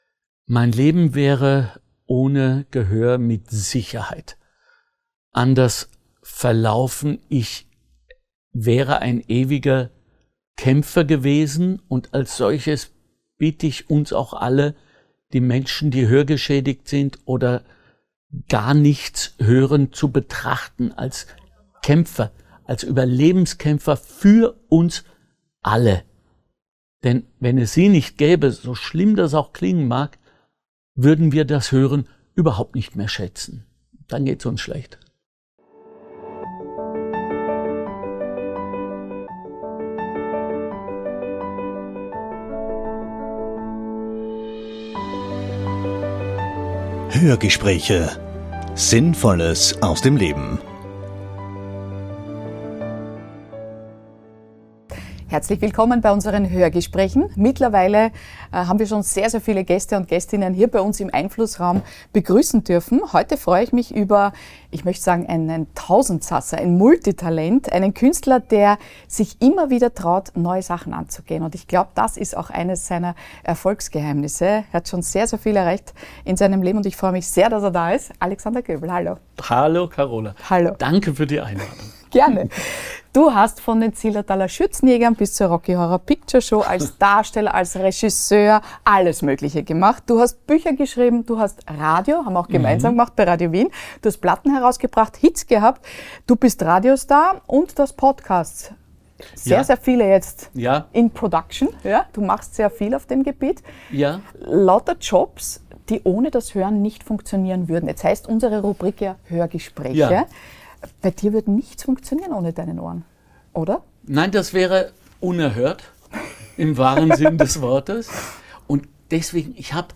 Schauspieler, Musical-Darsteller, Sänger, Theaterregisseur, Radio- und Podcast-Moderator, Komiker, Sprecher, Autor. Diese beispiellose künstlerische Vielfältigkeit hat Alexander Goebel zu der wissenden, reflektierten und lebenserfahrenen Persönlichkeit gemacht, die wir im Hörgespräch kennenlernen.